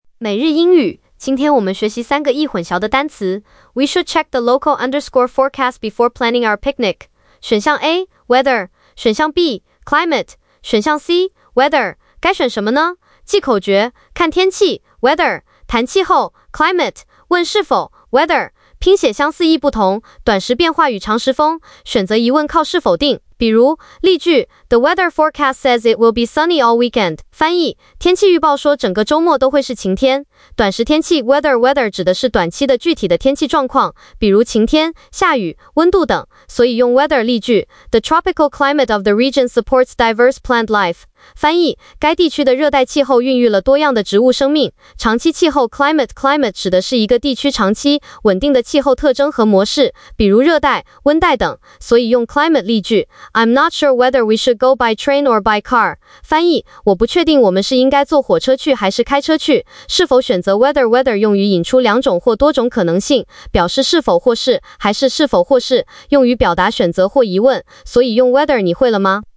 🎧 语音讲解